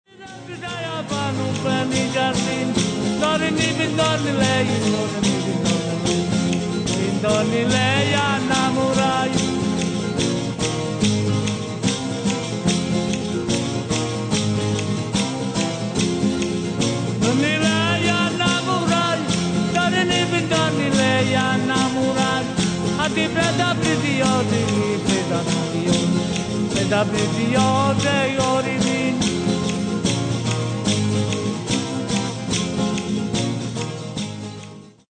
Chitarre battenti di Carpino.
I sonetti  e la taranta
Particolari sonorità sono ritmate dal "cantatore", la cui voce "di testa" con picchi acuti, accompagnata dalla mitica "chitarra battente", oltre che dalla "francese", dalle "castagnole" e dalla "tamorra", emerge anche a distanza.